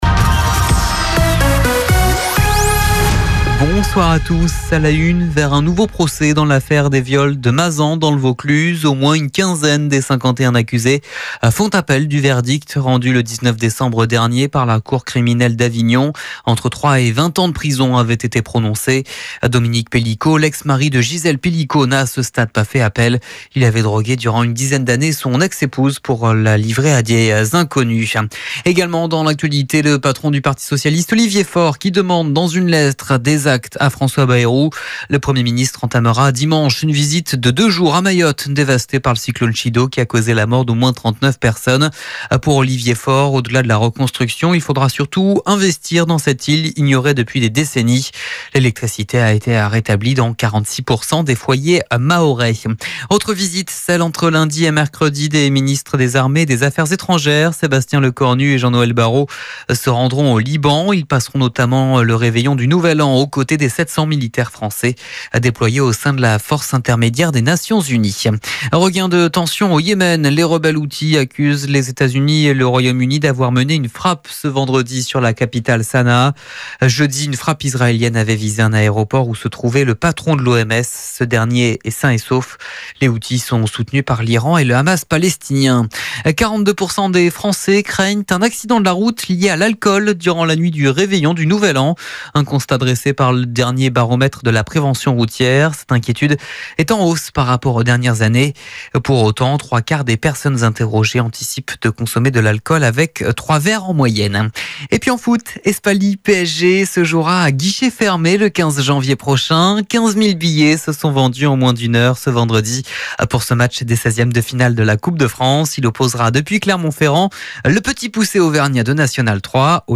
Entrevistador